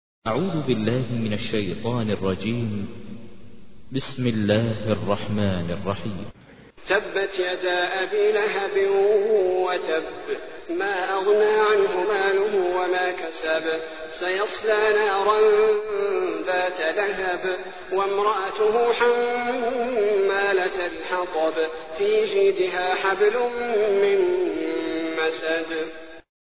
تحميل : 111. سورة المسد / القارئ ماهر المعيقلي / القرآن الكريم / موقع يا حسين